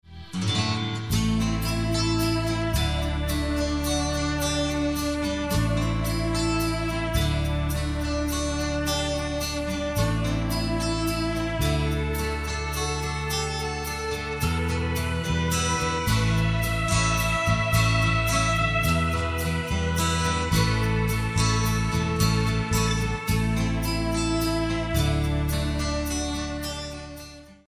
intense category-defying instrumentals